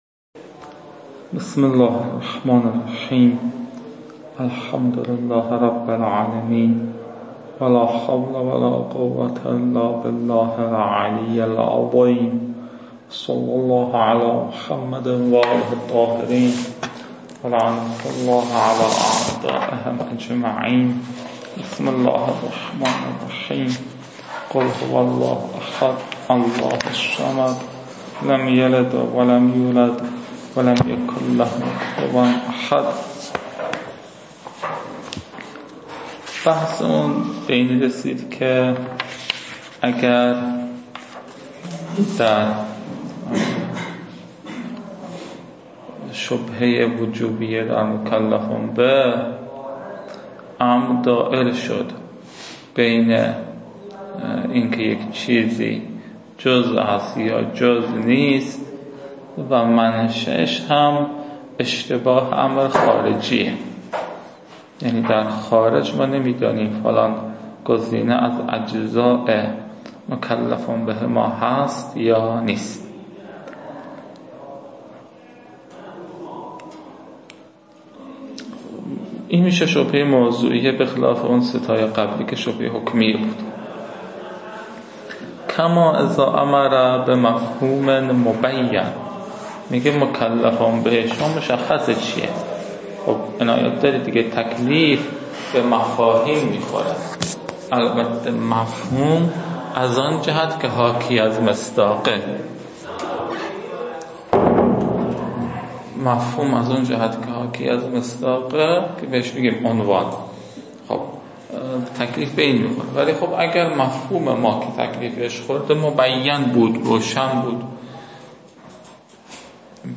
این فایل ها مربوط به تدریس مبحث برائت از كتاب فرائد الاصول (رسائل)